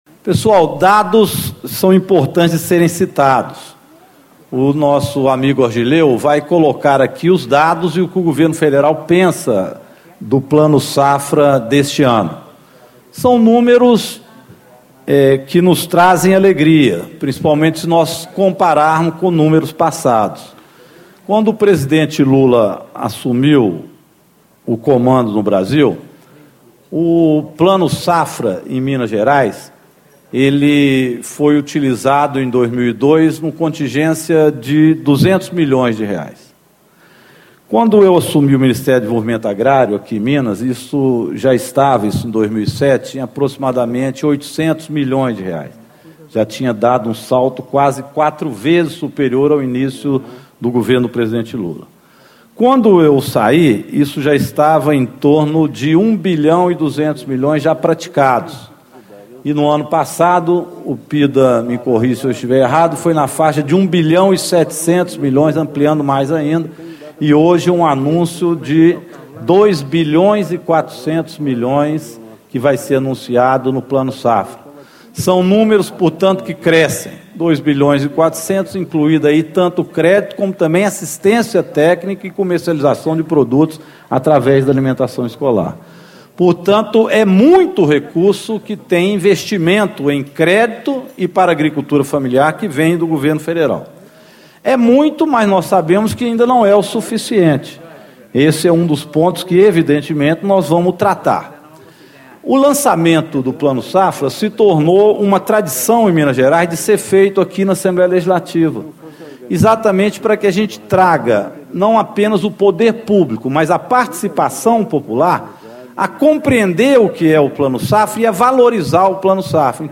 Deputado Rogério Correia (PT), autor do requerimento para realização do evento
Discursos e Palestras